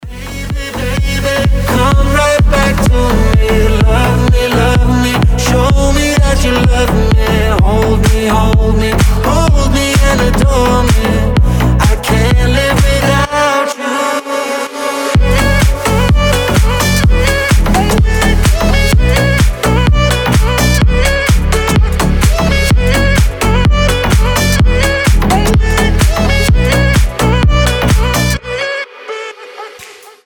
громкие
EDM
house
Vocal House
slap house